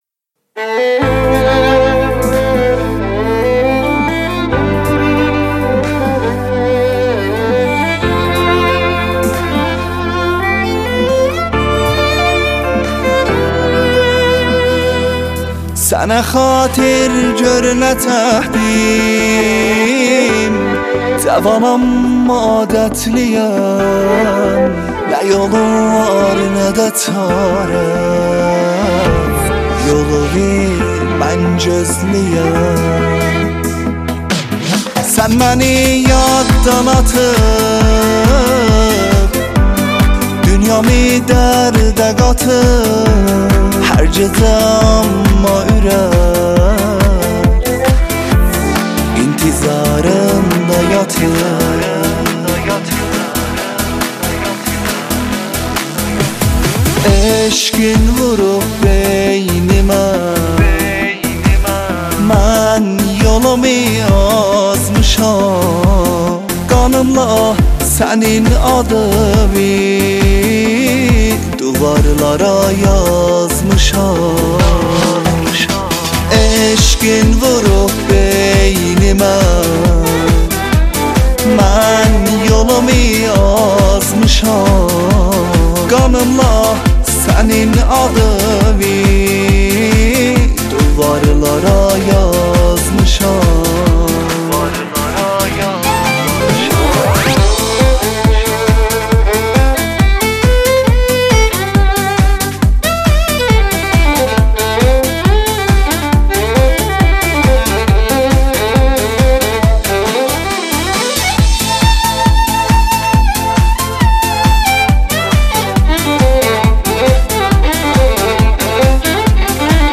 آهنگ محلی غمگین آذری
Sad Local Song of Azari